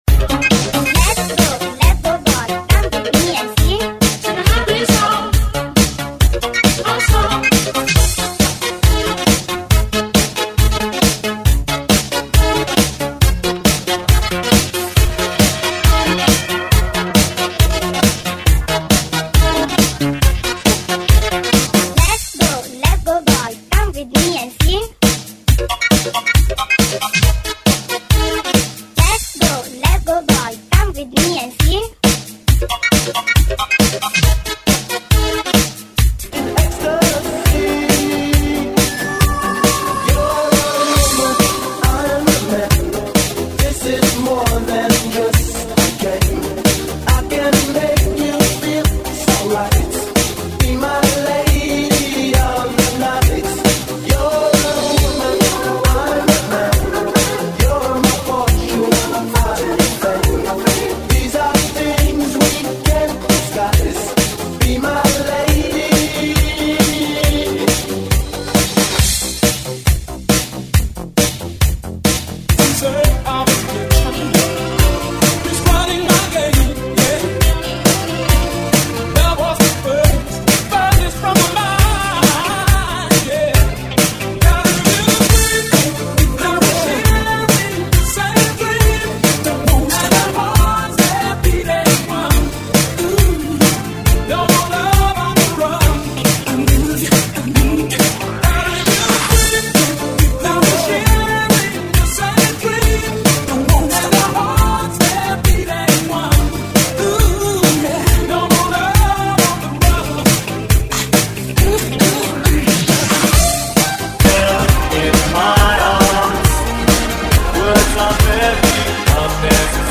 GENERO: TOP RADIO REMIX RETRO INGLES